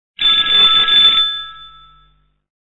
stariy_telefon.mp3